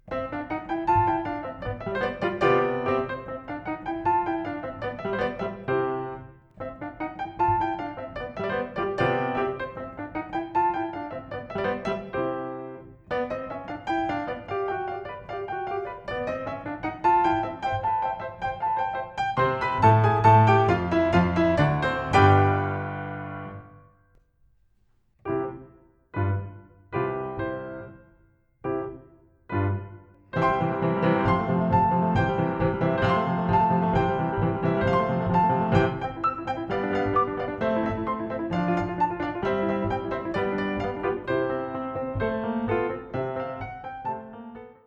Klavier-Arrangement des Orchesterparts